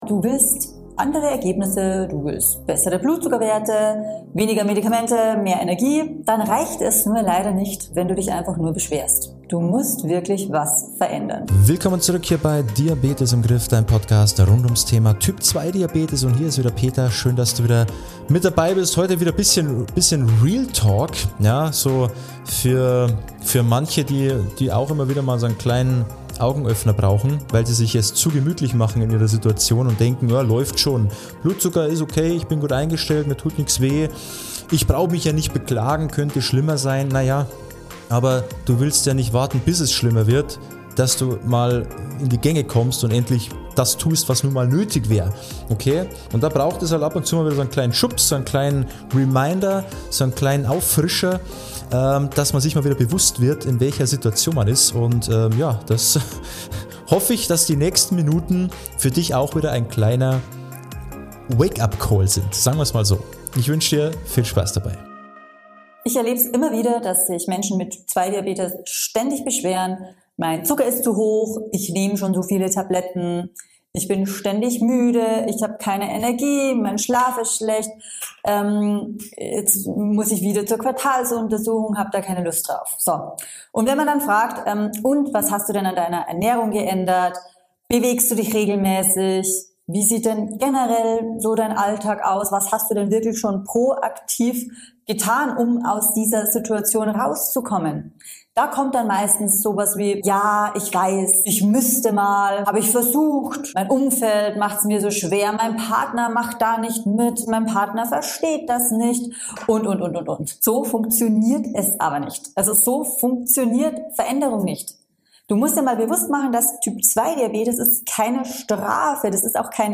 Ehrlich, direkt und motivierend.